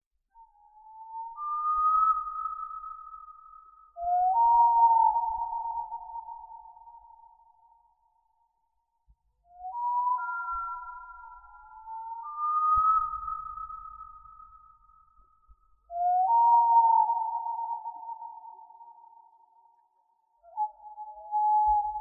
Звук длинноухой совы